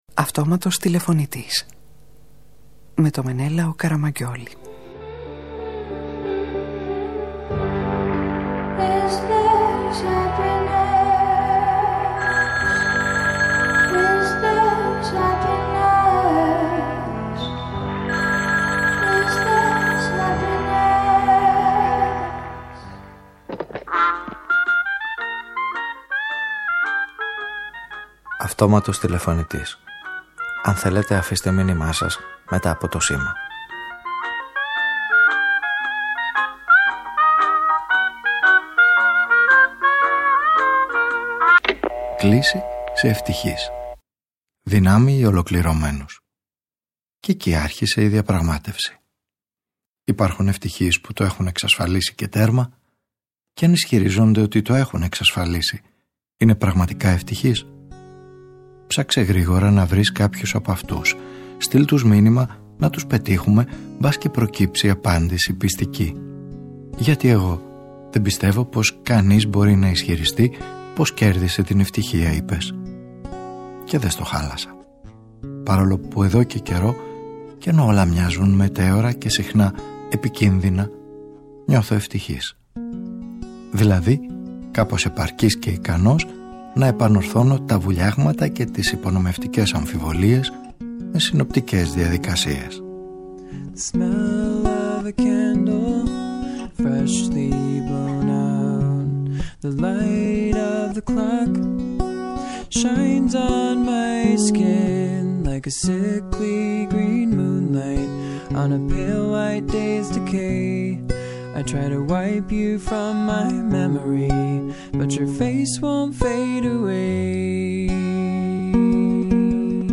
Οι ιστορίες τους γίνονται τα δύο σκέλη μιας ραδιοφωνικής ταινίας που υποστηρίζει και ενθαρρύνει όσους πολεμούν όλη τους τη ζωή για την αληθινή ευτυχία.